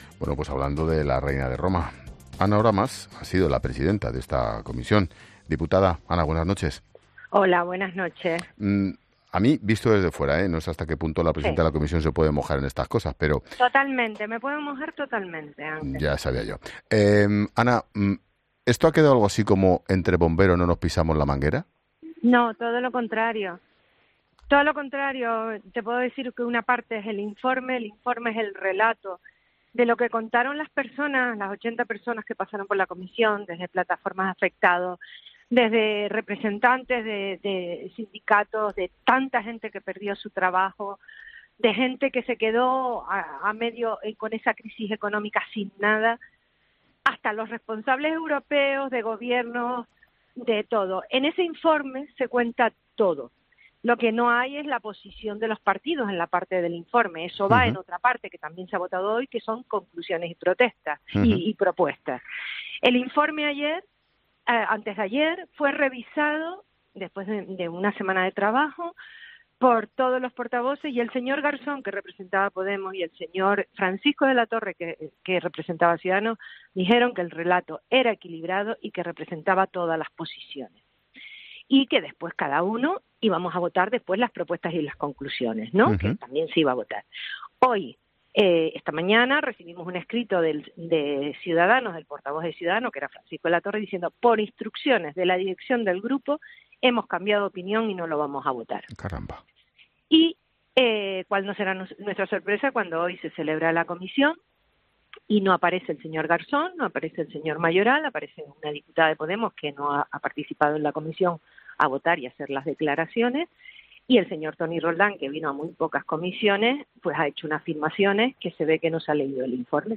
Ana Oramas ha explicado en 'La Linterna' con Ángel Expósito que “una parte es el informe del relato de lo que contaron los afectados, de mucha gente que perdió su trabajo. En ese informe se cuenta todo, y fue revisado por todos los partidos. Se dijo que era un relato equilibrado, pero hoy hemos recibido un escrito diciendo que habían cambiado de opinión y nos han dicho que no lo iban a votar”.